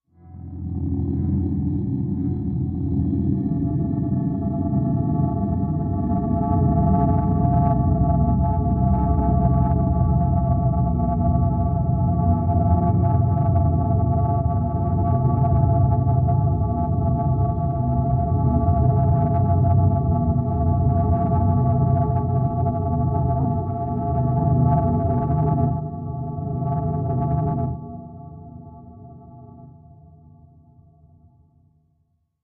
Cave Entrance Slow Cave Ambience Ghostly Wind